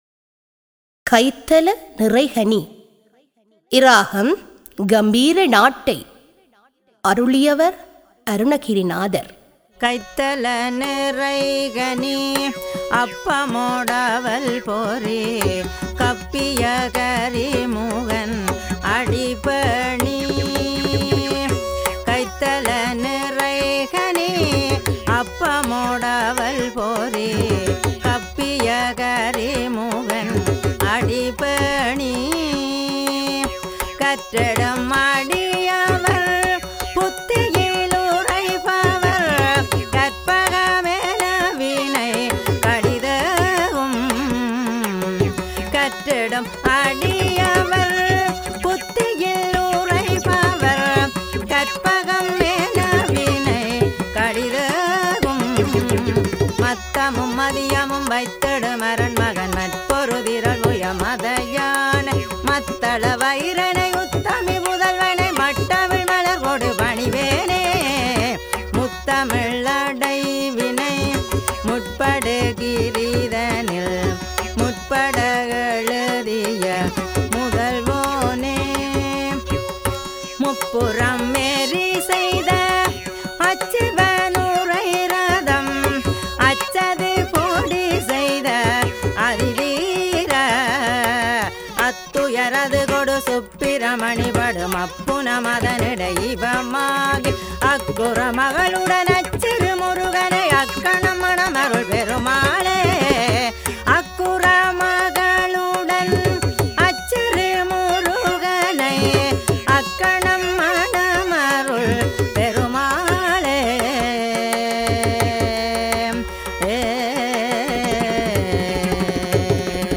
தரம் 9 இல் கல்வி பயிலும் சைவநெறிப் பாடத்தை கற்கும் மணவர்களின் நன்மை கருதி அவர்கள் தேவாரங்களை இலகுவாக மனனம் செய்யும் நோக்கில் இசைவடிவக்கம் செய்யப்பட்ட தேவாரப்பாடல்கள் இங்கே பதிவிடபட்டுள்ளன.